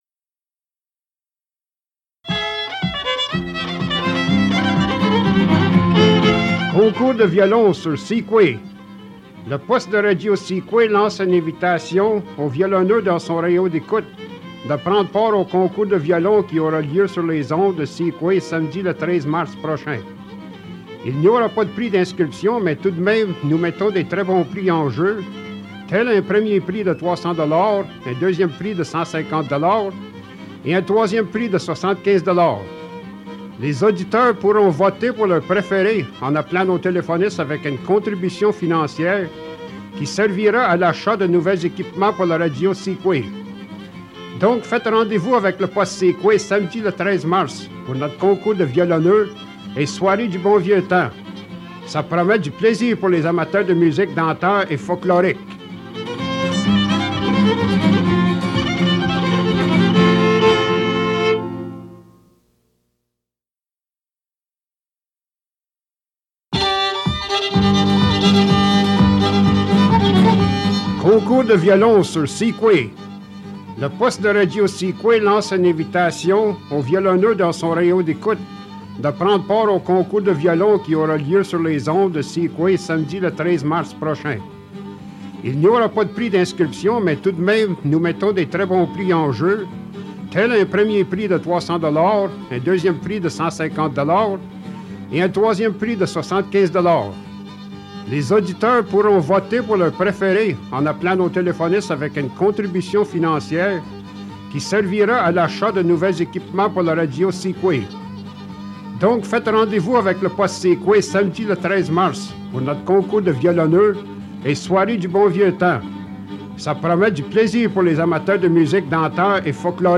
Fait partie de Live fiddle contest announcement